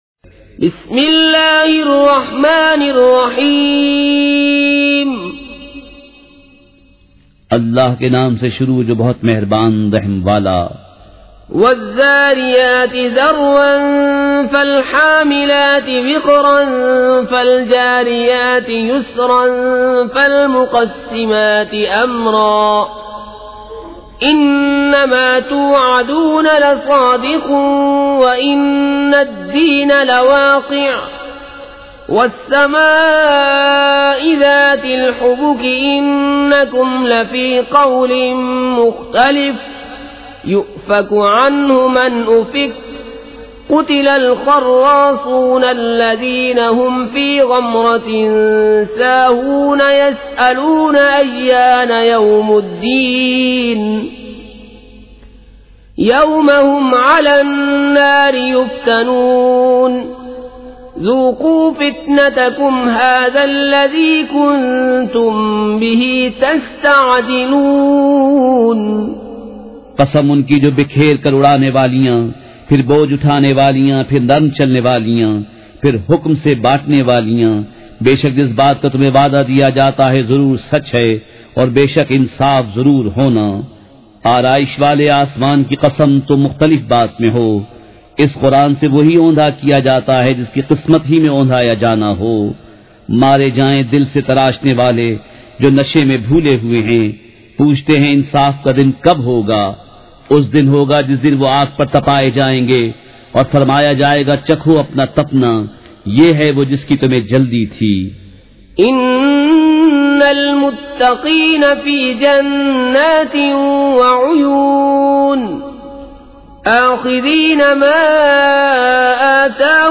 سورۃ الذاریات مع ترجہ کنزالایمان ZiaeTaiba Audio میڈیا کی معلومات نام سورۃ الذاریات مع ترجہ کنزالایمان موضوع تلاوت آواز دیگر زبان عربی کل نتائج 2100 قسم آڈیو ڈاؤن لوڈ MP 3 ڈاؤن لوڈ MP 4 متعلقہ تجویزوآراء